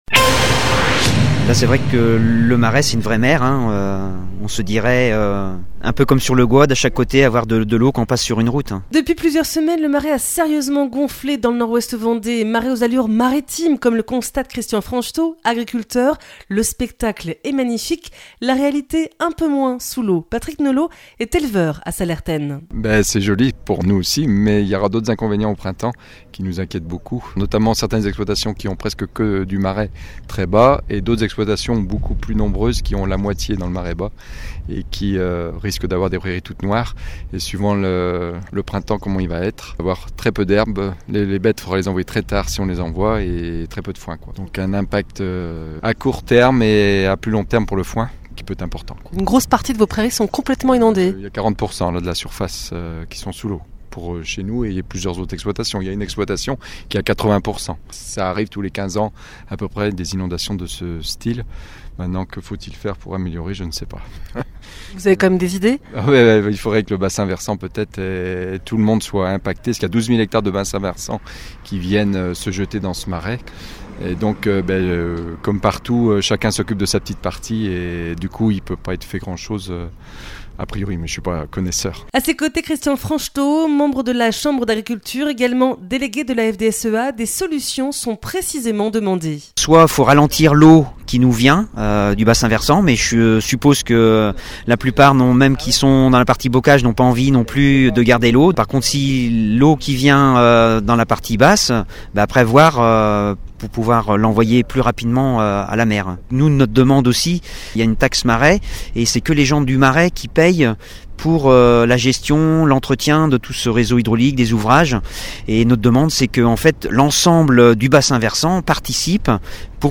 Dans le Nord-Ouest Vendée maraîchin, les précipitations records de ces dernières semaines ont sérieusement fait monter le niveau des eaux. Reportage à Sallertaine